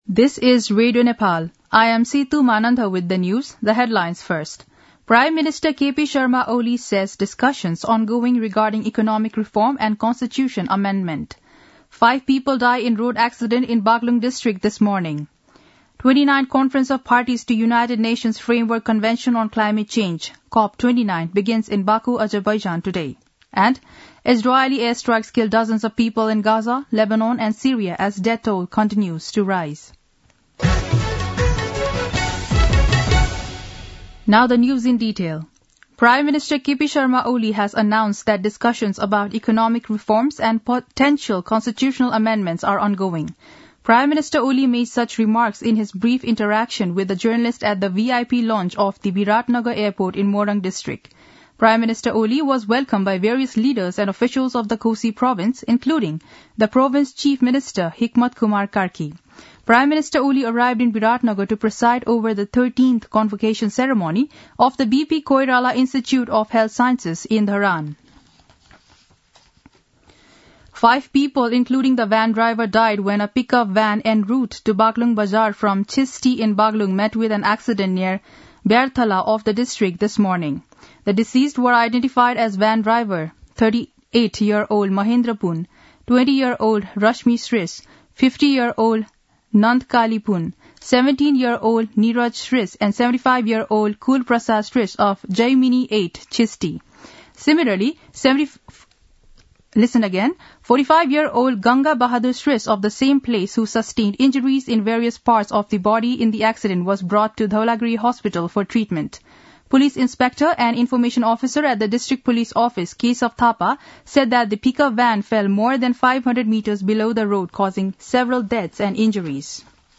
दिउँसो २ बजेको अङ्ग्रेजी समाचार : २७ कार्तिक , २०८१
2-pm-english-news-1-1.mp3